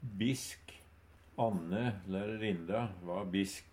DIALEKTORD PÅ NORMERT NORSK bisk morsk, sinna, arg Ubunde han-/hokj.